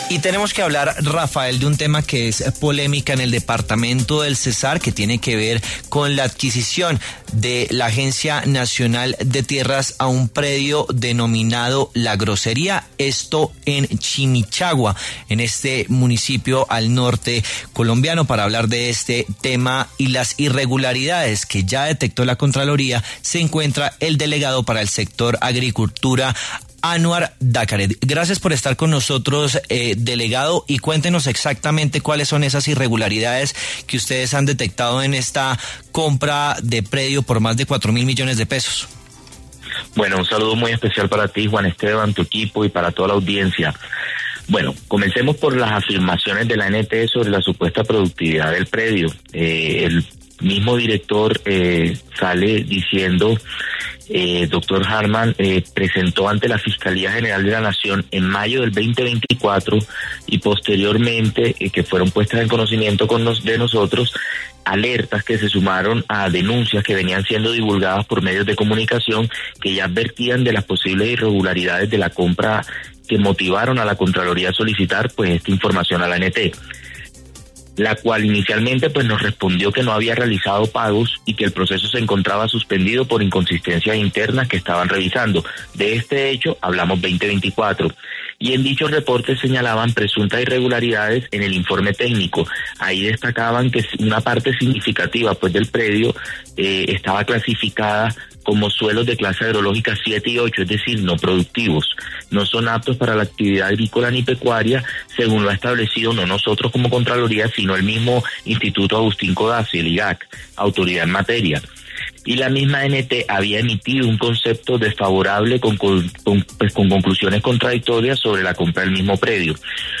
Anwar Daccarett, contralor delegado para el Sector Agropecuario, explicó en W Fin De Semana algunos de los hallazgos, presuntamente de irregularidades, encontrados en la compra del predio.